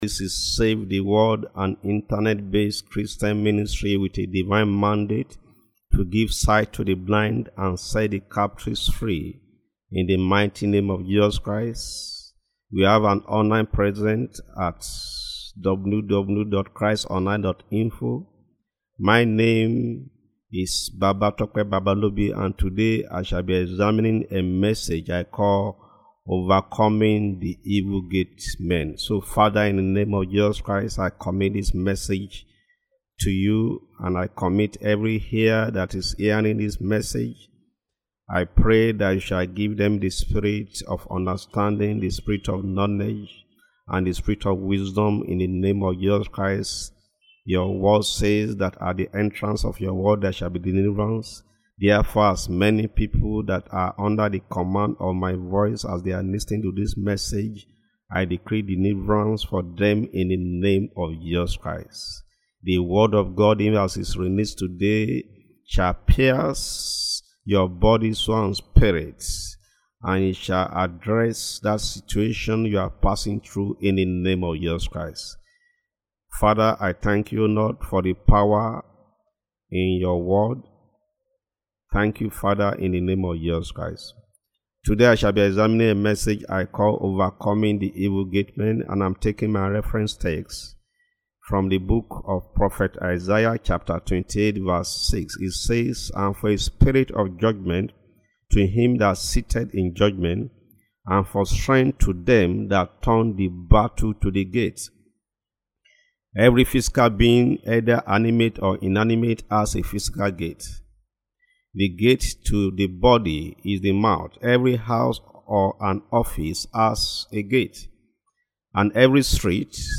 Spiritual warfare audio sermon: Battles at the gate of breakthrough - Save the World Ministry